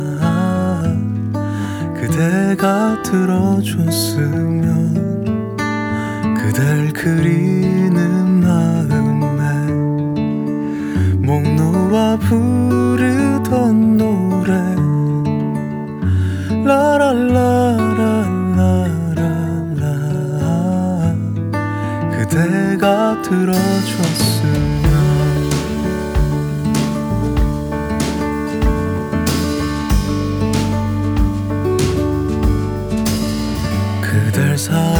Жанр: K-pop / Поп